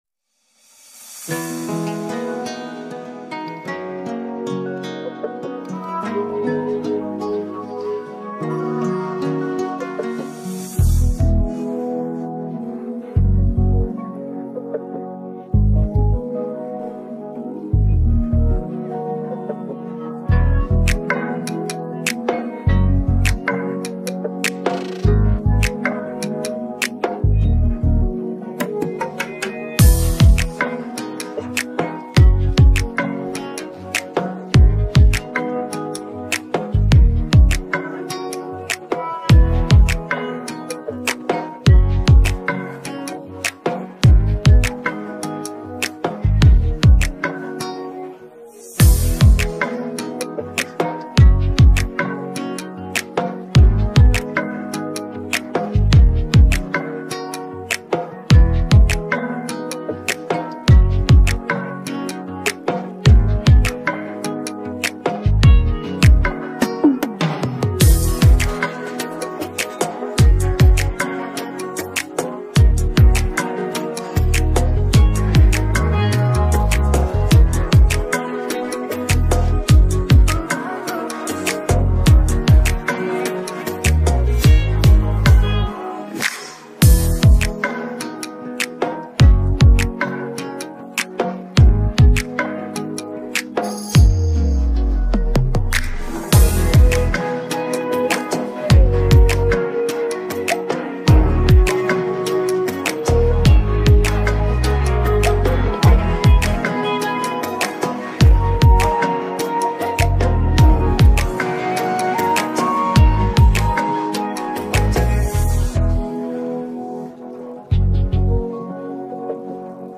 نسخه بی کلام